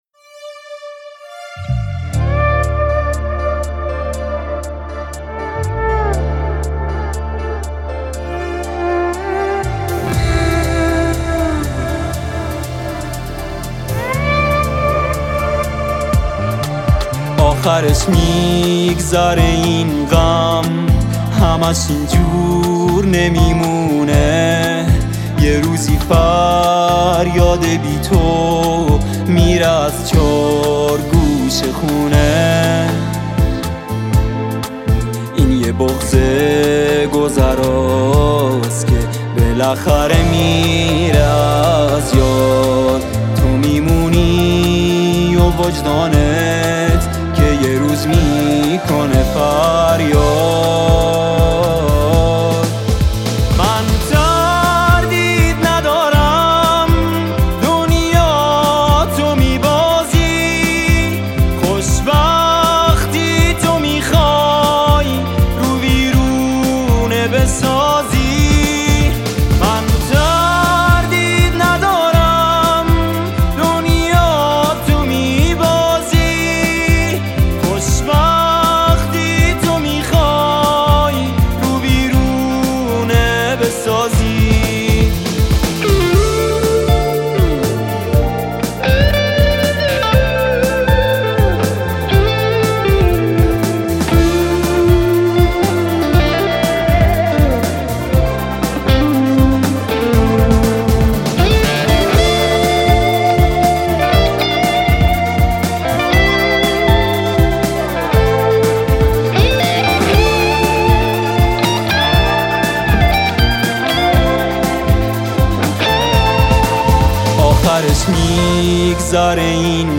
گیتار بیس
گیتار الکتریک
تک اهنگ ایرانی